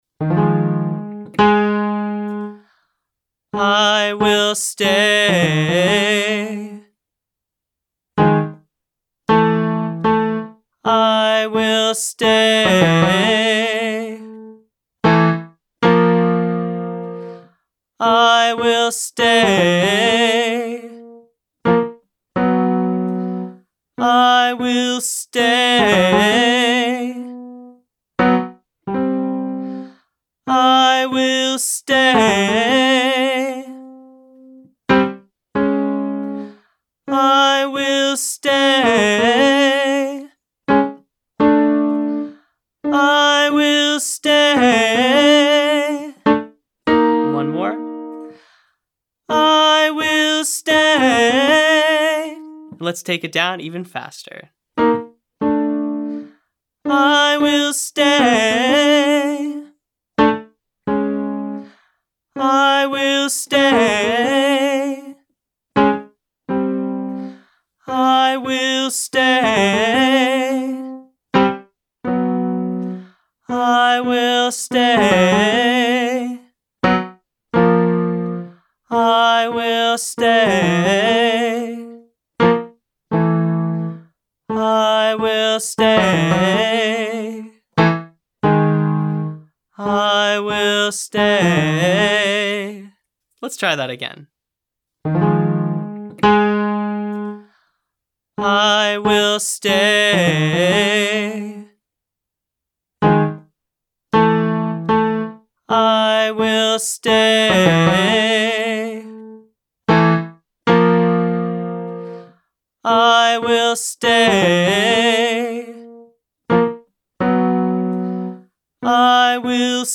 Riffing Exercises
Welcome to Part 3, where we'll put our vocal agility to the test with popular riffing style exercises.
• Sing the riff staccato-style, identify the momentum, and then sing it smoothly.
Vocal Agility Daily Warmup for Low Voice-4A